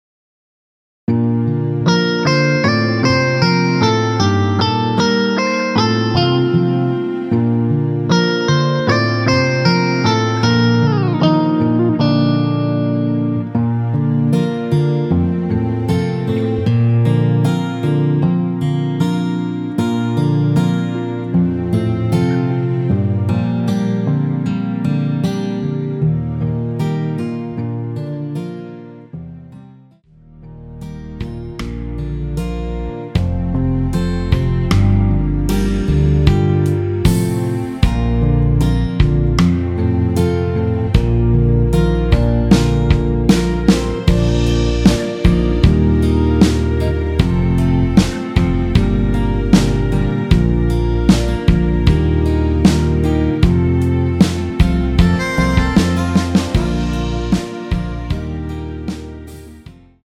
원키에서(-3)내린 (1절앞+후렴)으로 진행되는 MR입니다.
Bb
앞부분30초, 뒷부분30초씩 편집해서 올려 드리고 있습니다.
중간에 음이 끈어지고 다시 나오는 이유는
위처럼 미리듣기를 만들어서 그렇습니다.